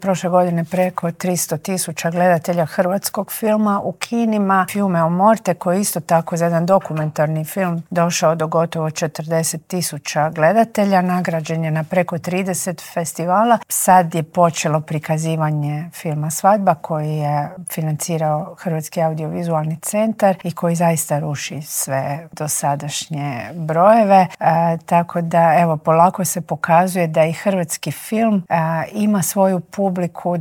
Treba 'ohladiti glave' i spustiti tenzije, zaključila je na kraju intervjua ministrica Obuljen Koržinek.